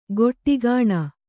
♪ goṭṭi gāṇa